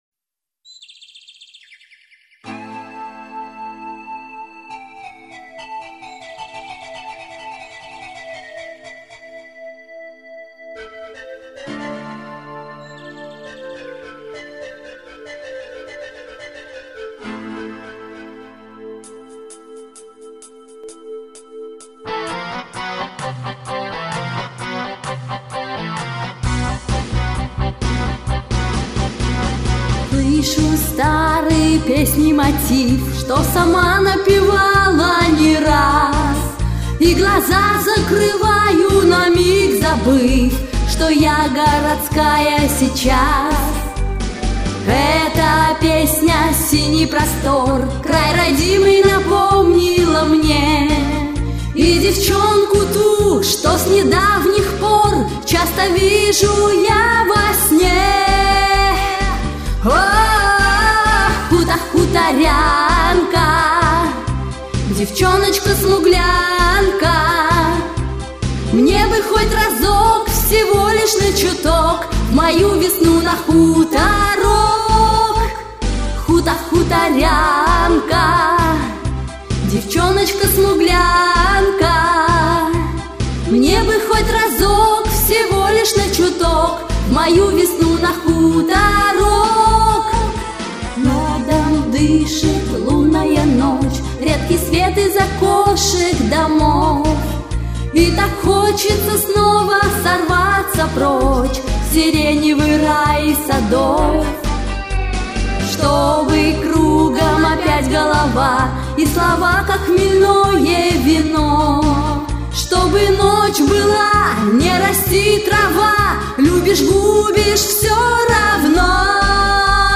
голос сливается с музыкой